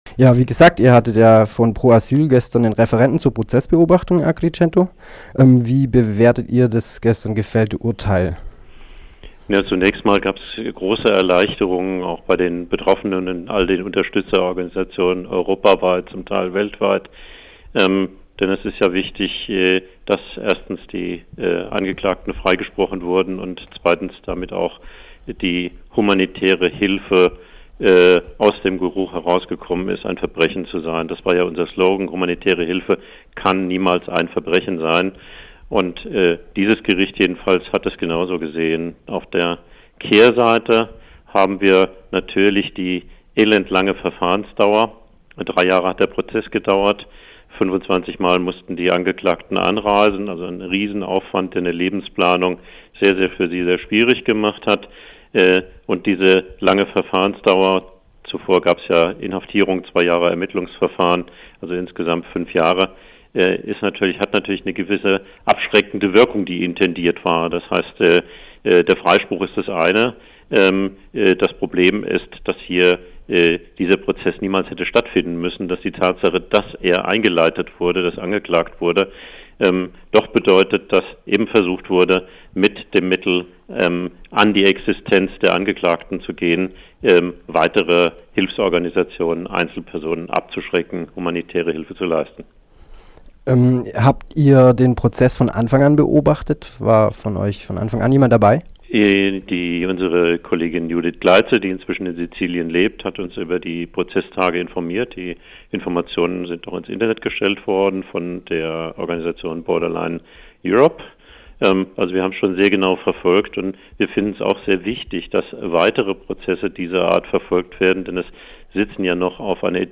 Interview über den Fall und Urteilsverkündung von Cap Anamur/Italien